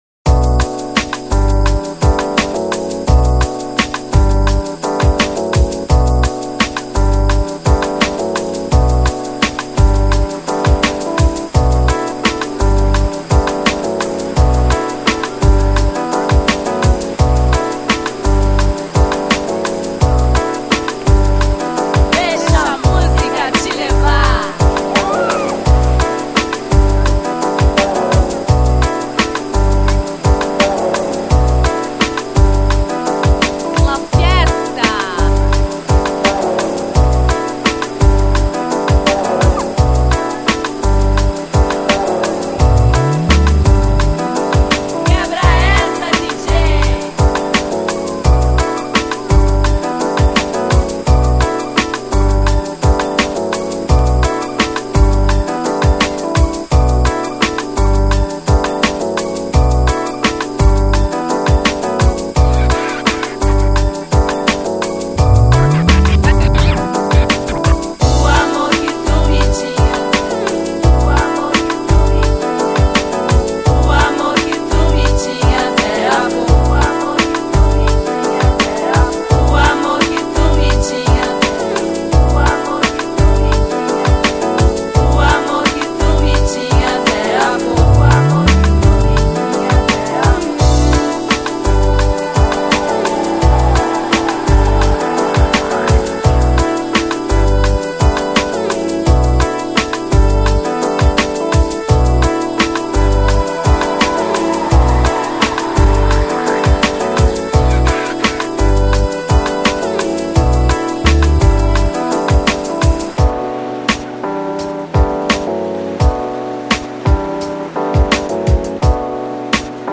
Жанр: Downtempo, Lounge, Chillout